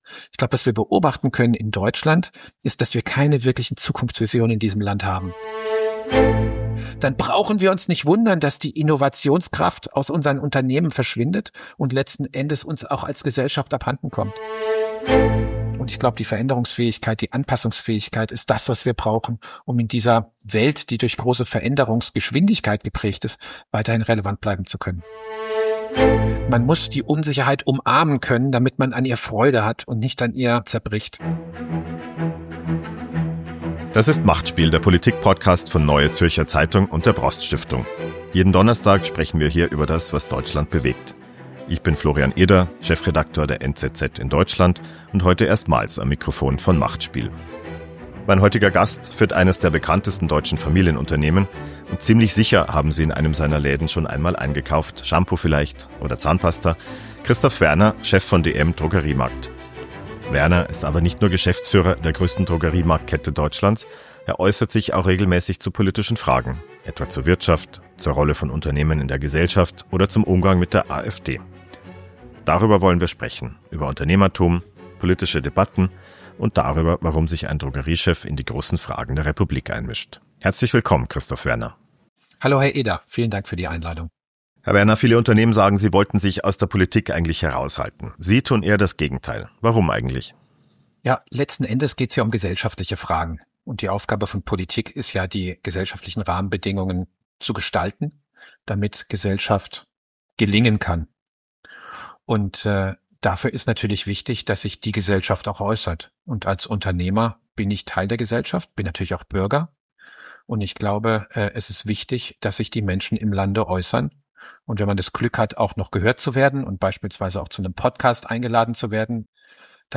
Ein Gespräch über Vertrauen in der Politik, Debattenkultur und die Frage, welche Rolle Unternehmen in gesellschaftlichen Diskussionen spielen.